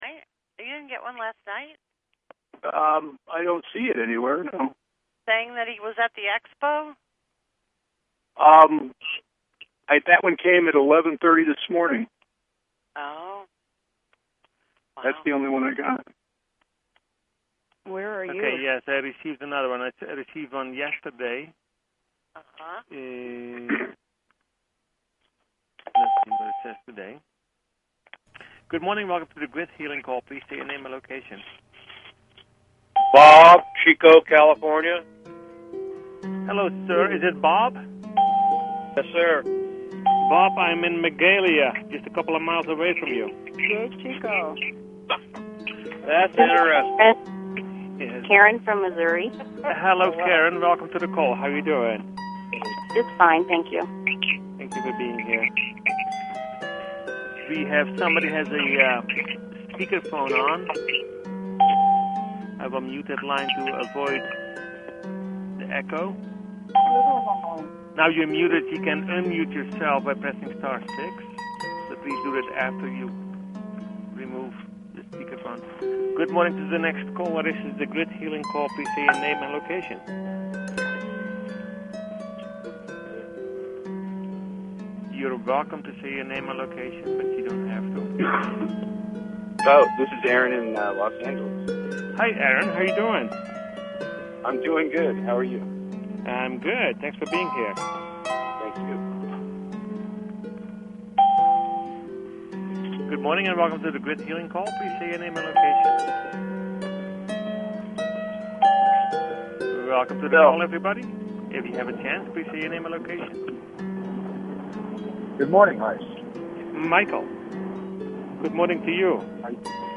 Talk Show Episode, Audio Podcast, Personal_Planetary_Healing_Meditation and Courtesy of BBS Radio on , show guests , about , categorized as